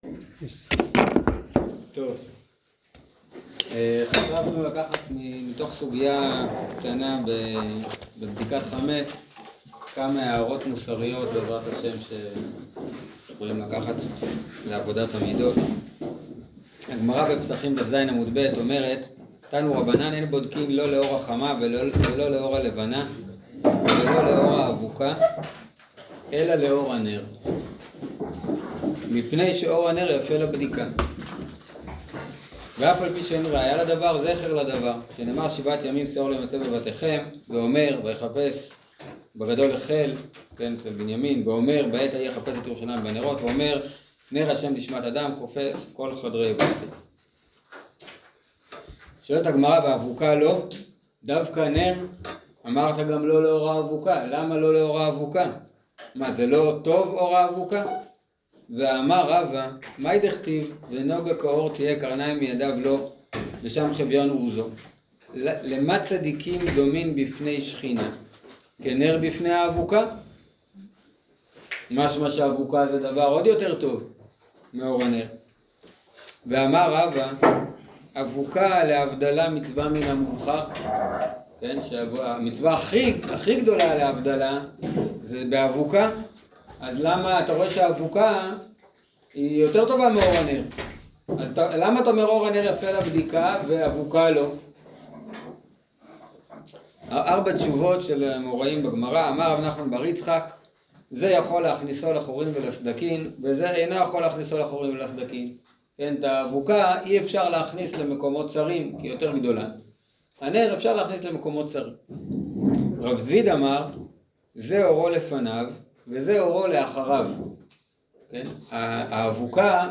שיעור אור הנר יפה לבדיקה